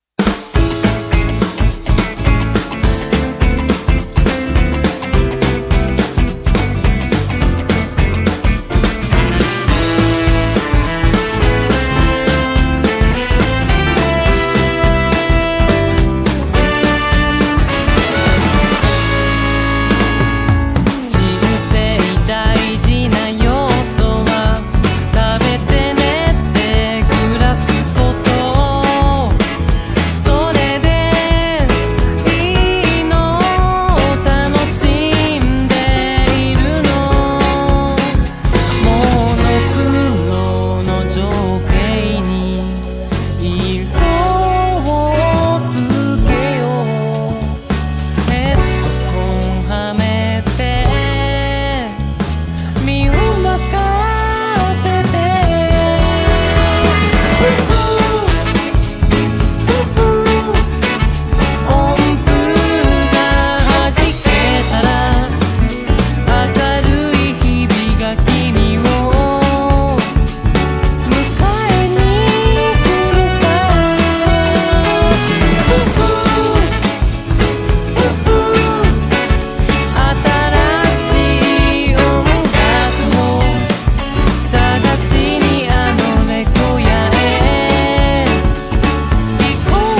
形式 : 7inch / 型番 : / 原産国 : JPN
# POP# CITY POP / AOR# 90-20’S ROCK
モータウン・ビートで駆け抜ける爽やかでソウルフルな仕上がりのグルーヴィー・ポップ・ソングです！